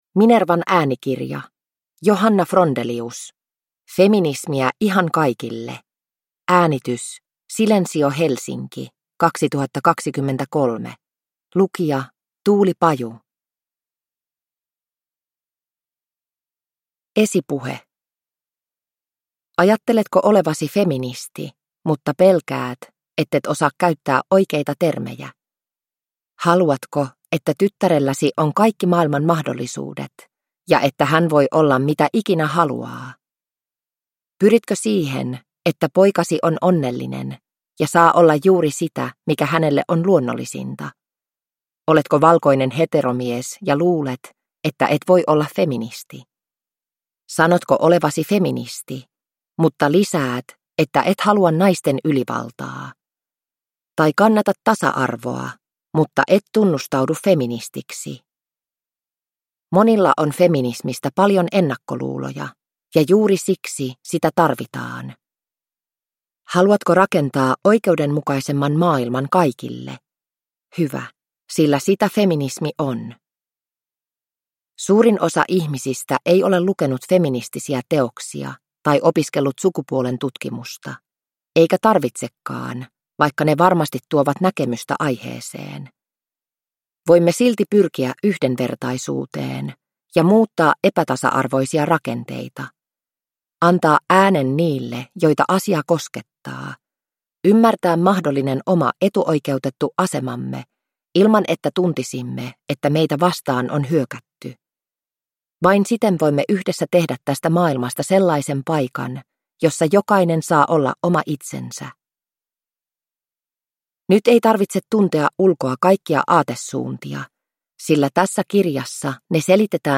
Feminismiä ihan kaikille – Ljudbok – Laddas ner